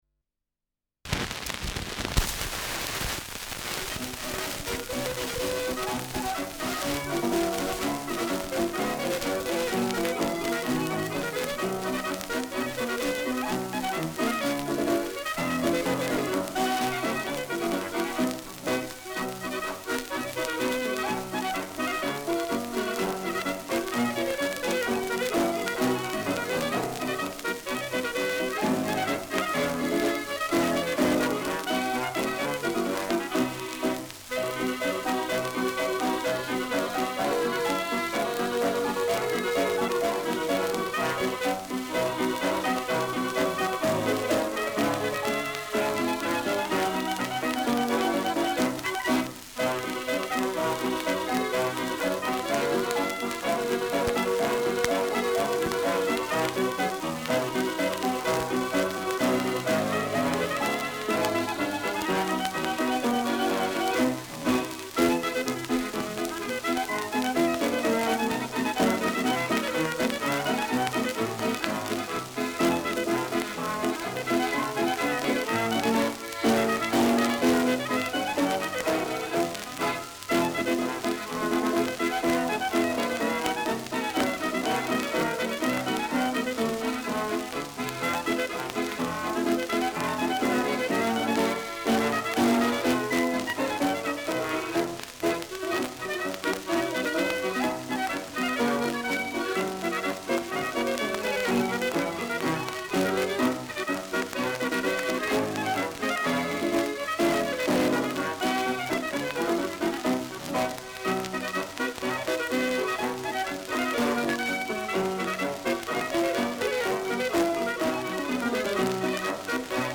Schellackplatte
Ländlerkapelle* FVS-00018